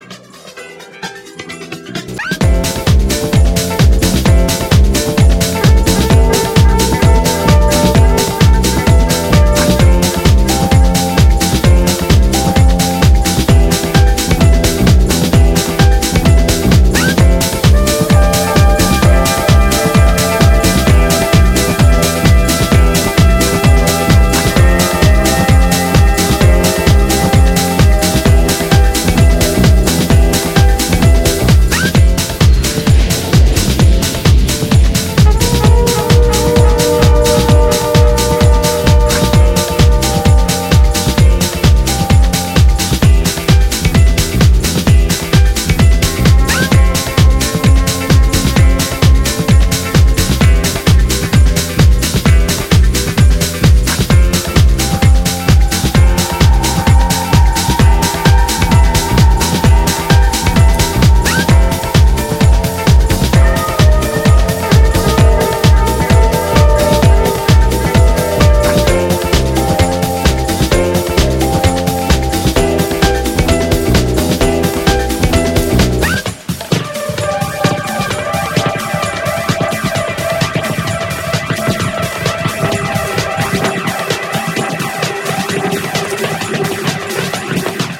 どこかノスタルジックなメロディーを湛えた
執拗なディスコサンプルの反復とエフェクトの応酬で畳み掛ける、BPM130前後のキラーチューン全4曲を収録。